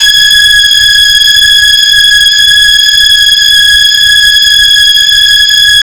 08 Skanners 165 Ab.wav